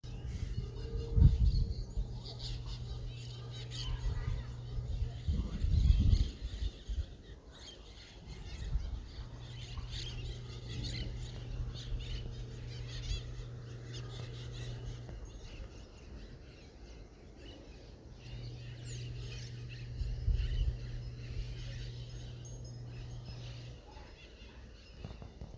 AMANECER CERRO ESPIRITU SANTO ALAJUELA